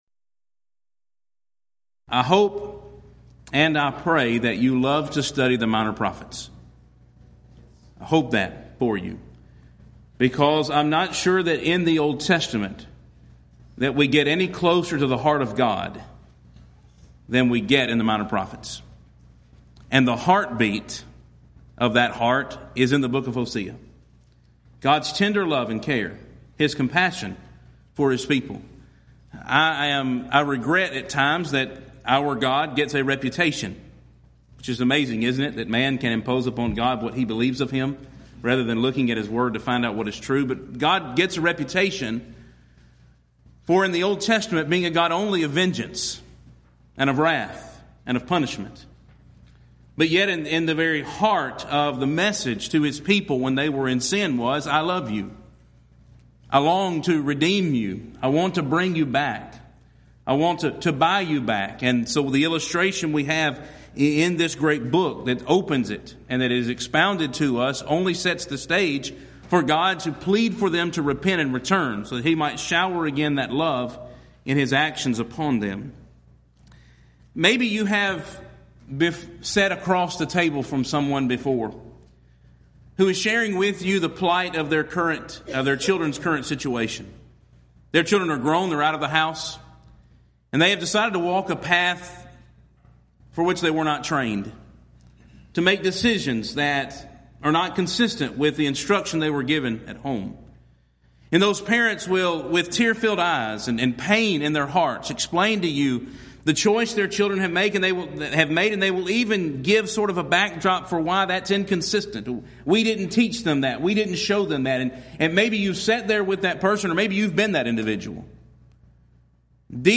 Event: 12th Annual Schertz Lectures Theme/Title: Studies in the Minor Prophets
lecture